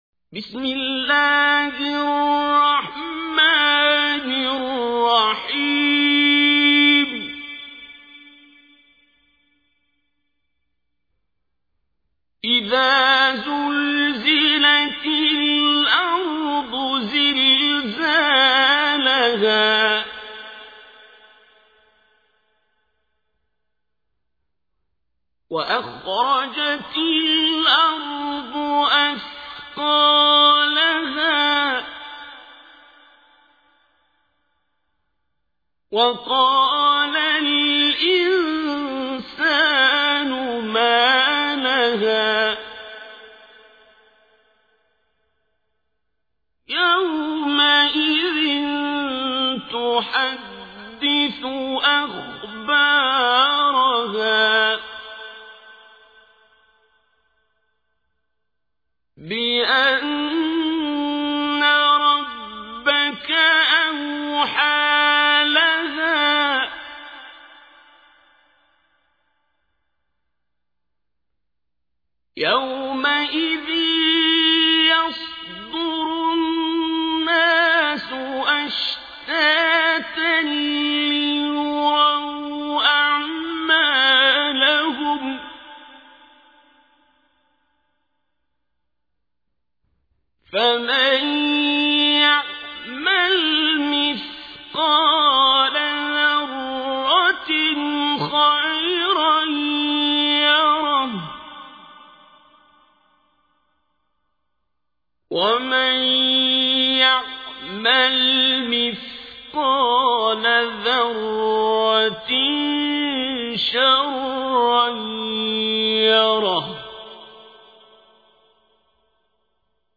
تحميل : 99. سورة الزلزلة / القارئ عبد الباسط عبد الصمد / القرآن الكريم / موقع يا حسين